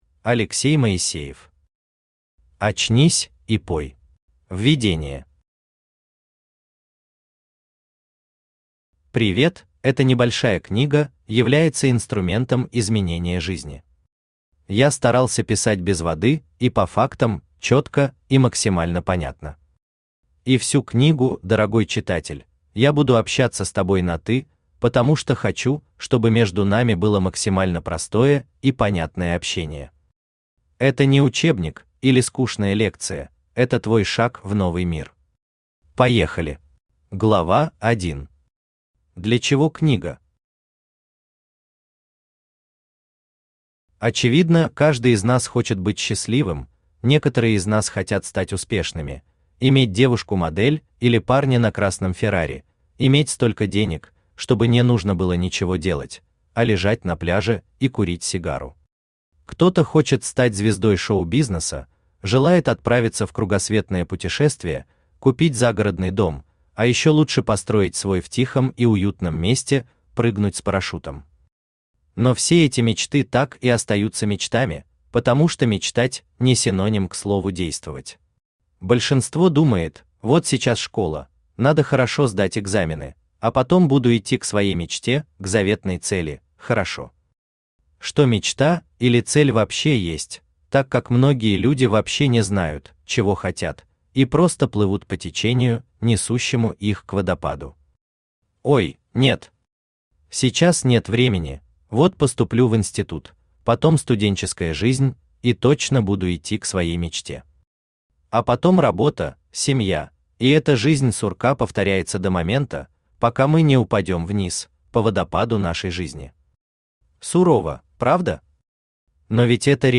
Аудиокнига Очнись и пой | Библиотека аудиокниг
Aудиокнига Очнись и пой Автор Алексей Моисеев Читает аудиокнигу Авточтец ЛитРес.